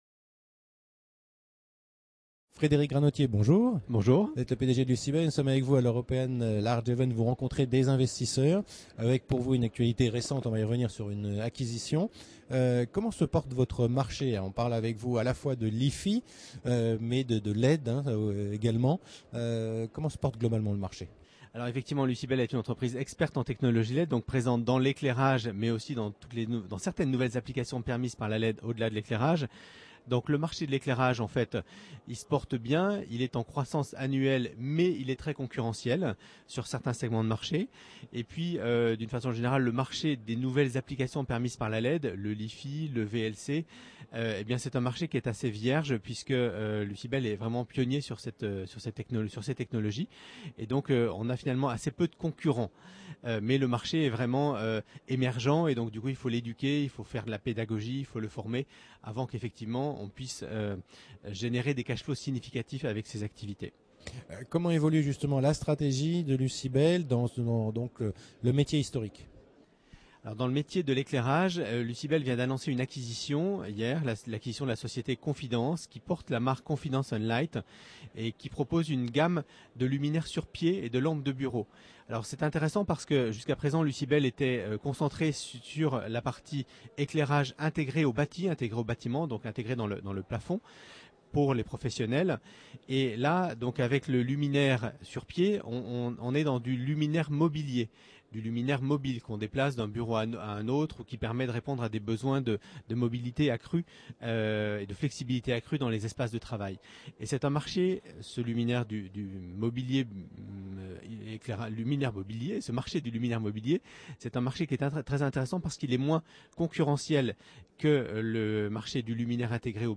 La Web Tv rencontre les dirigeants au Paris – European Large et Midcap Event.